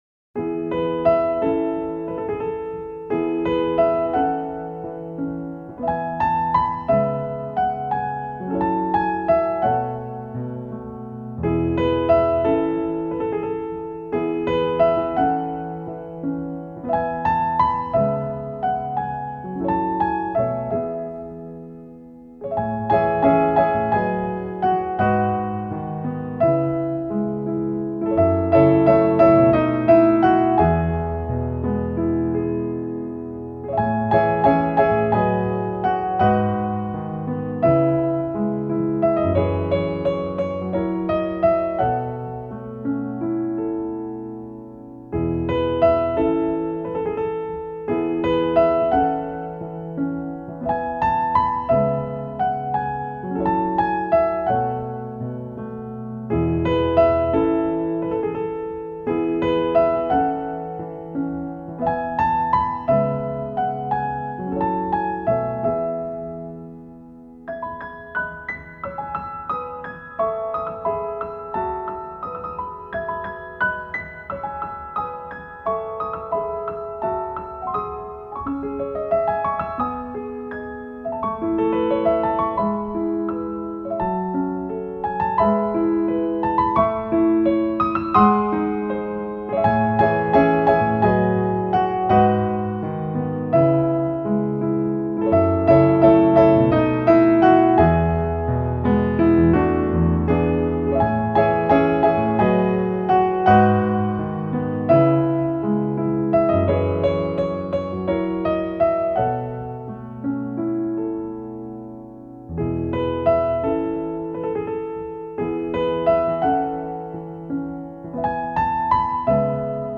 (P/S: Có một chút nhạc nhé 😉 )